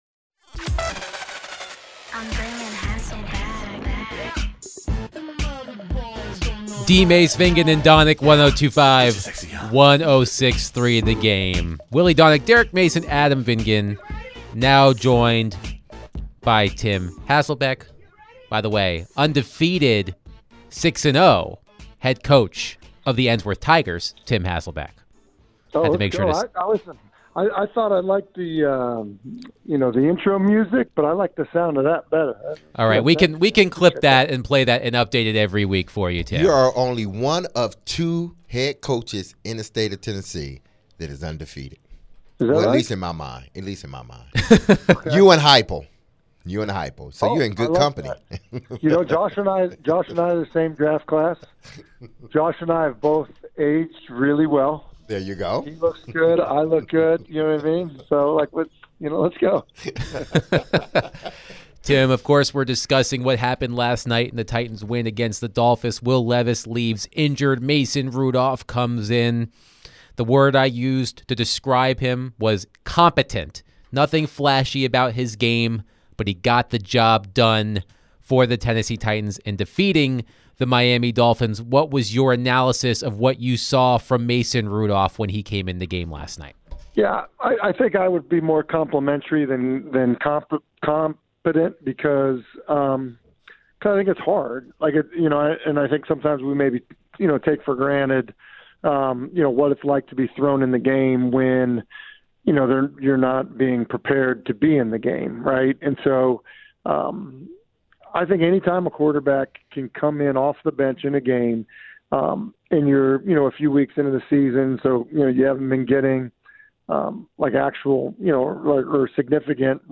ESPN NFL Analyst & Ensworth head football coach Tim Hasselbeck joined the show to share his thoughts on the Titans' first win. How does he think Mason Rudolph played last night?